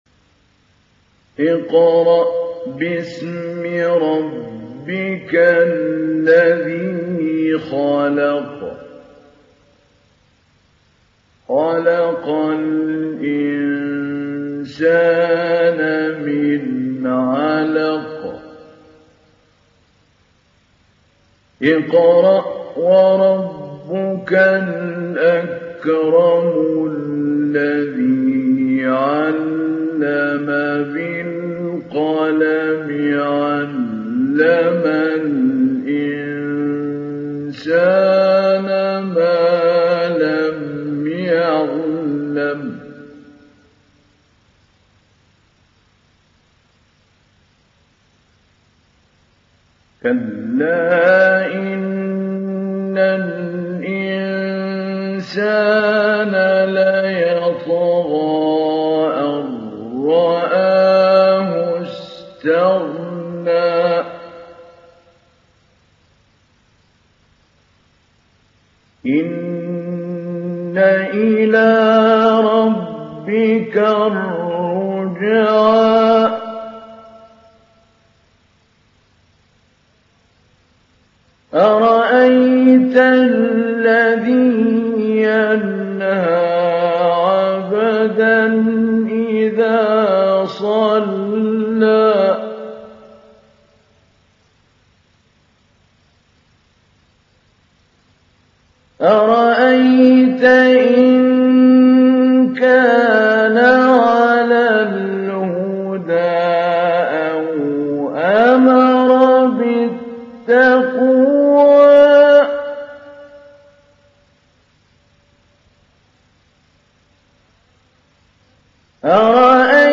ডাউনলোড সূরা আল-‘আলাক্ব Mahmoud Ali Albanna Mujawwad